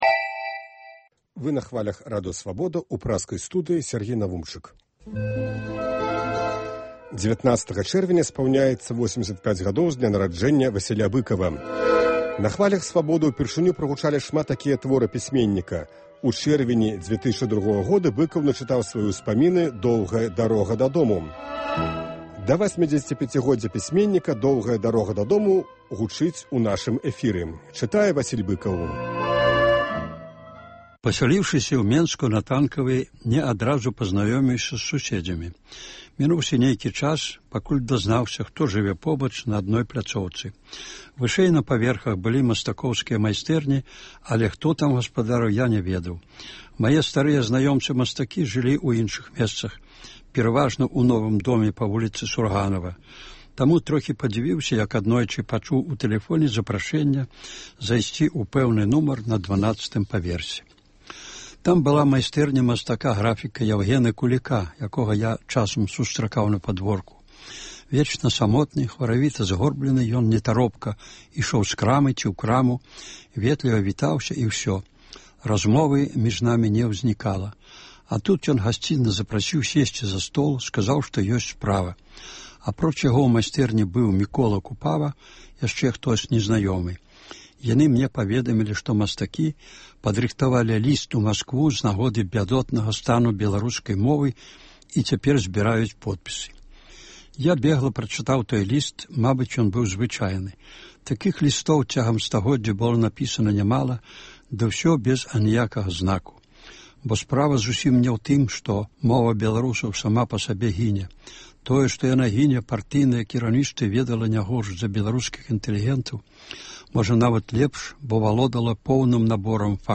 19 чэрвеня спаўняецца 85 гадоў з дня нараджэньня Васіля Быкава. Сёлета ў чэрвені штодня ў нашым эфіры гучыць “Доўгая дарога дадому” ў аўтарскім чытаньні.